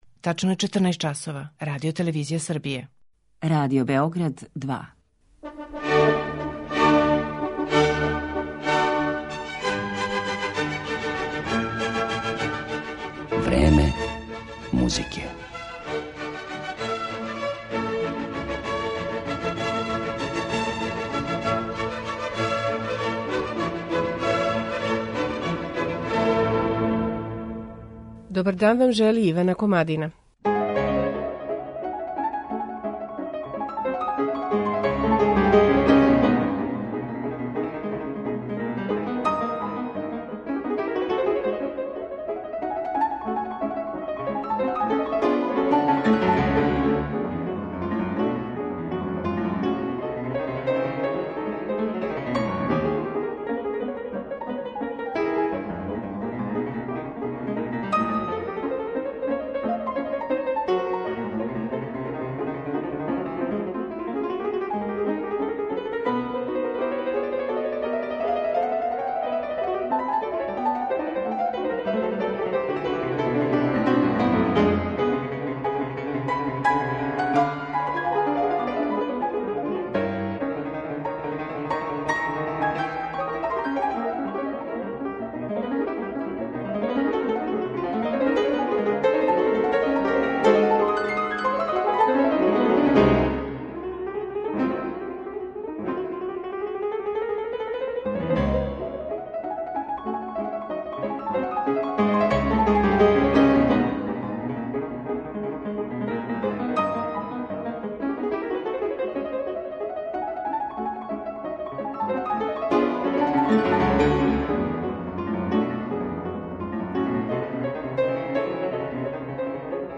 У данашњем Времену музике откривамо како под прстима Мељникова звучи седам различитих клавира док интерпретира дела Фредерика Шопена, Игора Стравинског, Франца Шуберта, Роберта Шумана и Клода Дебисија.